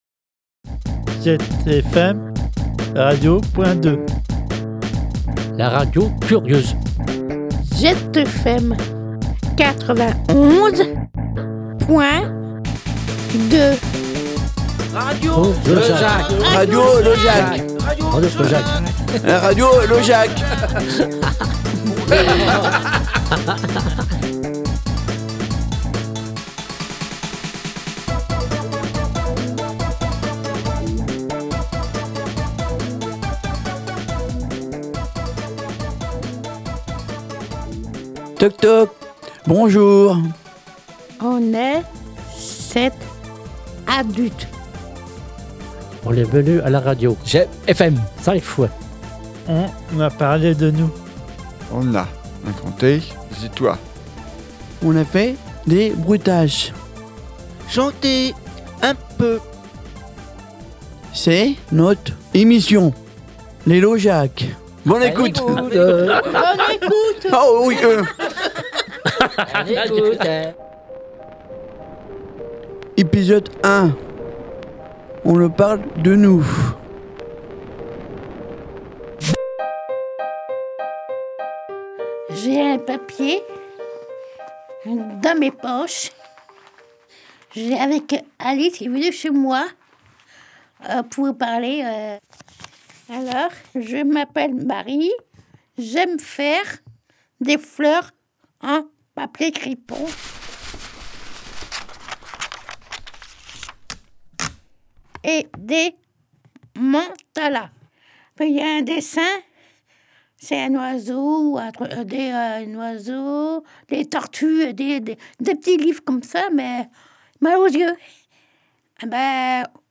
Ils ont appris à poser leur voix, parler d’eux, faire des jingles, raconter des histoires et faire des bruitages.
On a passer tout cela dans notre mixer sonore pour vous proposer cette belle émission sous le titre « radio Logeac ».